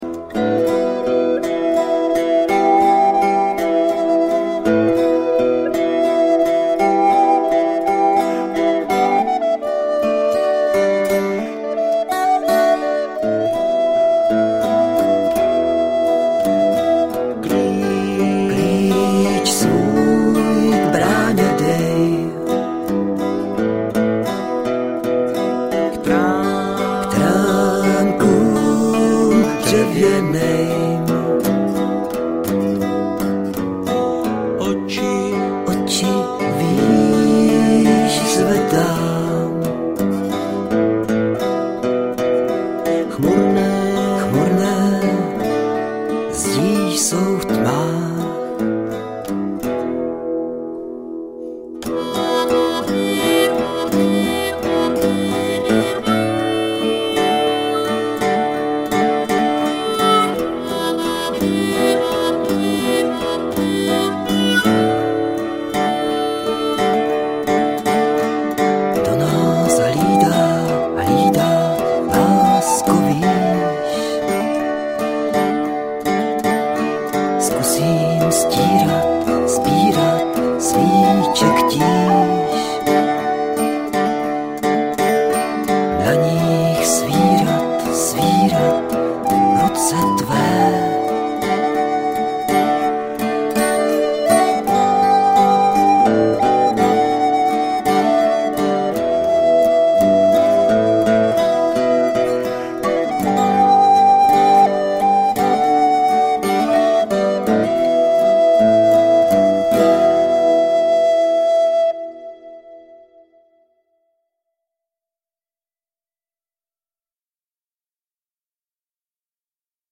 Dvě ukázky (úryvky) z dávné historie jsou z dema